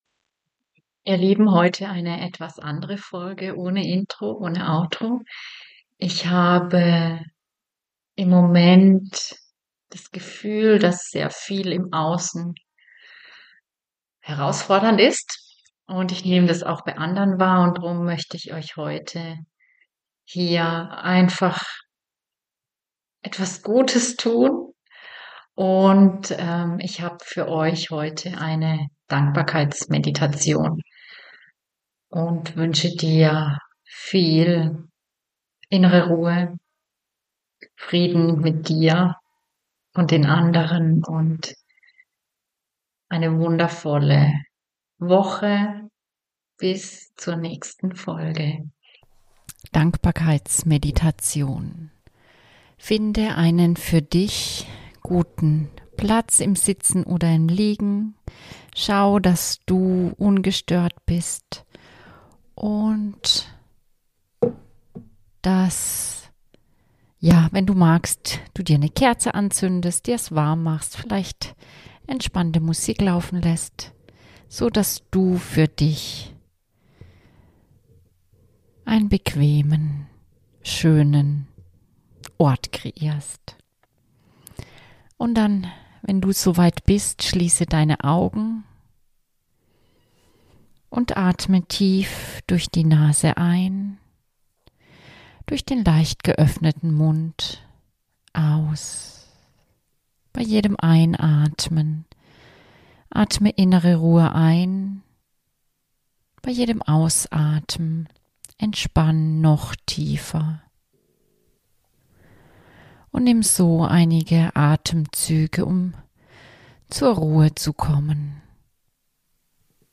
In dieser Folge erwartet dich eine besonders stille Einladung, ohne Intro, ohne Outro. Ich schenke dir eine liebevoll geführte Dankbarkeitsmeditation, die dich durch innere Landschaften und sommerliche Bilder zu mehr Ruhe, Frieden und Verbundenheit führt.